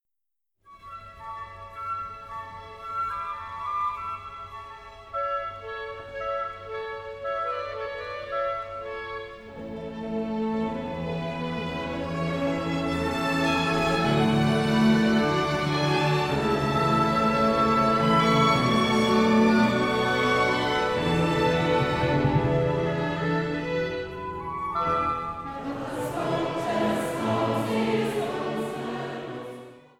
Kinderchor, Klavier